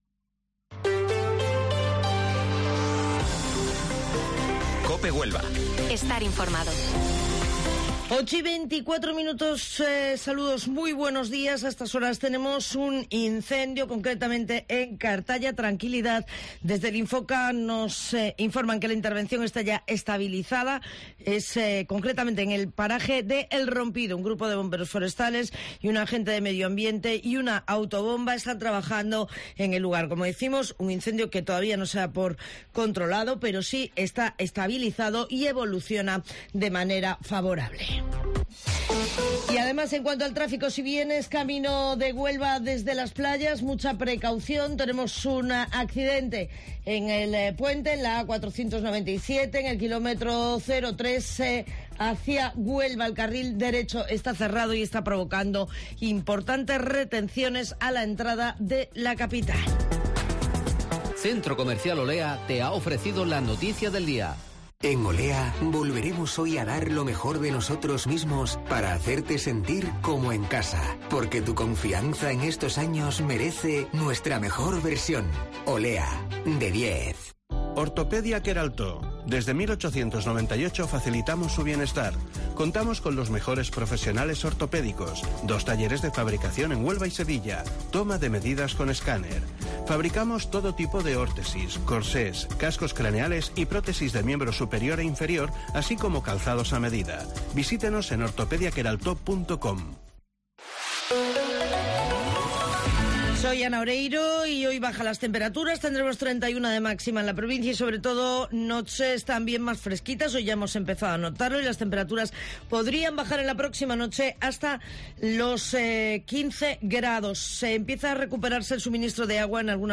Informativo Matinal Herrera en Cope 25 de julio